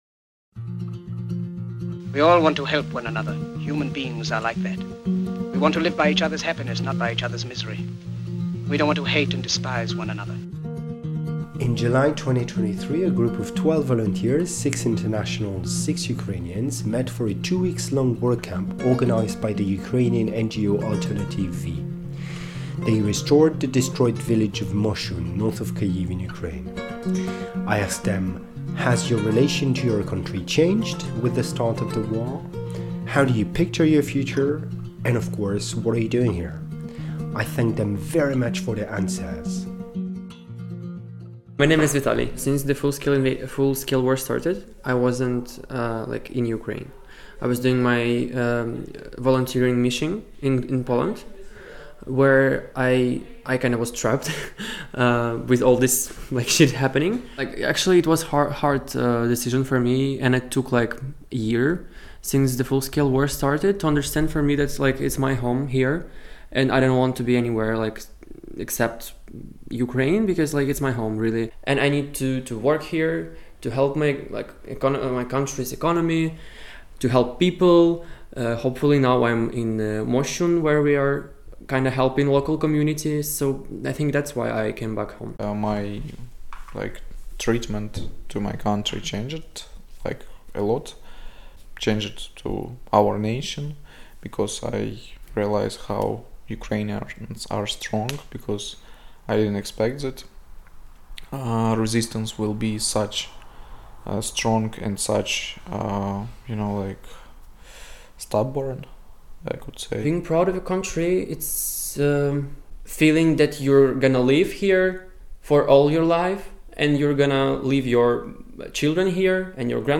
Podcast audio sur un chantier en Ukraine en 2023
Les volontaires ont restauré un village à Moschun, au nord de l’Ukraine, à environ 30 km de Kyiv, pendant deux semaines, et dans l’audio ils/elles livrent leur ressenti sur leur relation avec l’Ukraine, les raisons de leur participation à ce chantier et leur vision de l’avenir. Au total, c’était un groupe de 11 volontaires (6 internationaux·ales et 5 locaux·ales) et la plupart des participant·e·s avaient plus de 30 ans.